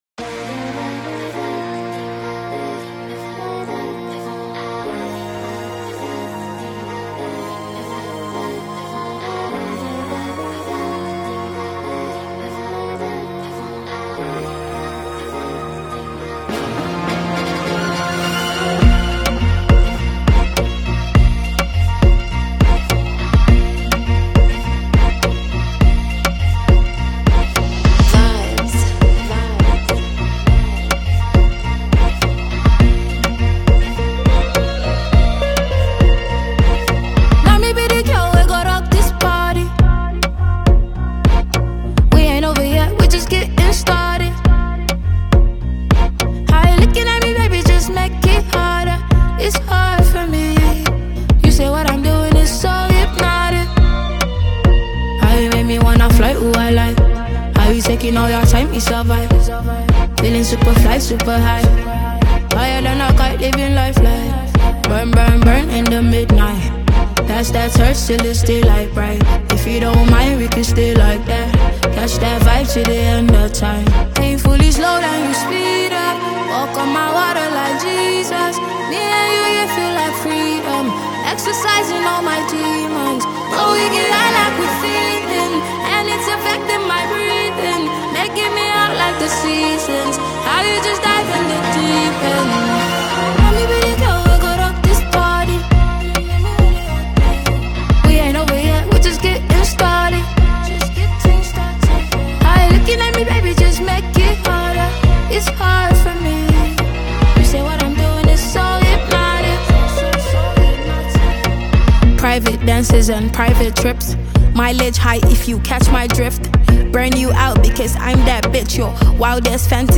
Uprising Nigerian female singer and composer